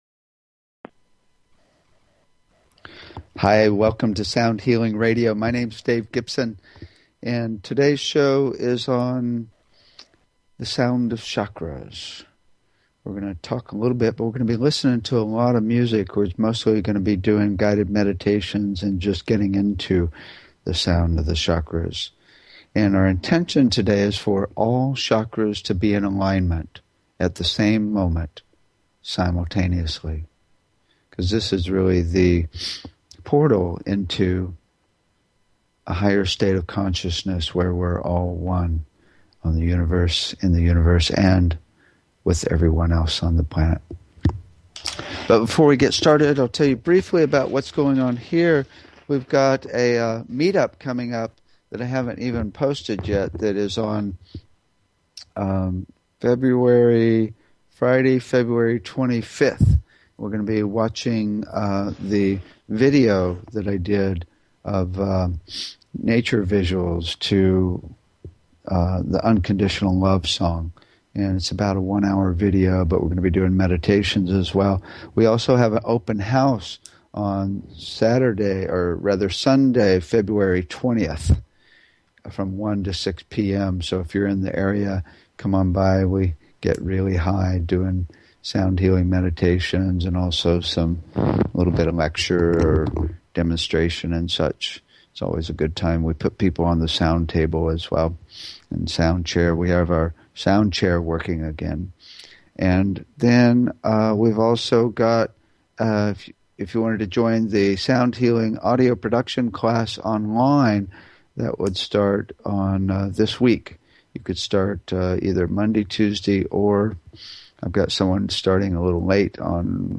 Talk Show Episode, Audio Podcast, Sound_Healing and Courtesy of BBS Radio on , show guests , about , categorized as
We'll end with a Chakra meditation to hear and feel all chakras at the same time taking you into an amazing state of bliss.